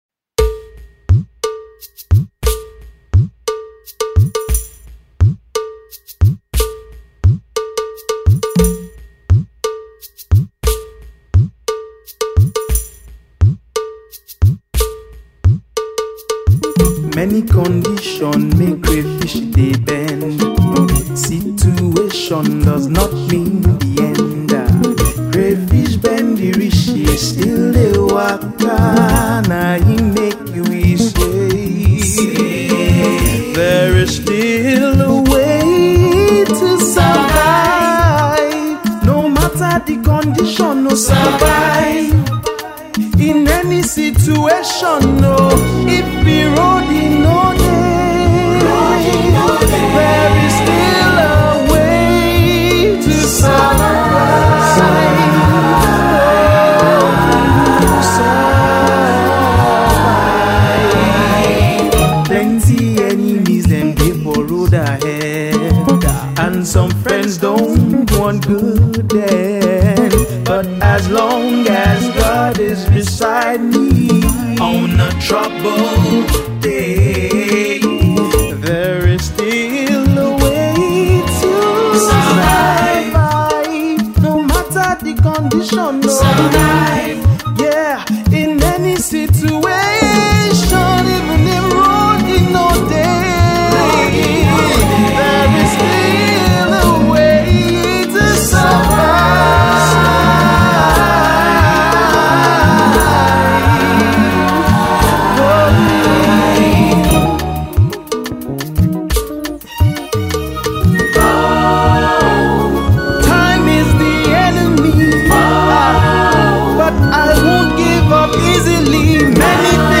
Folk-tinged Africana Pop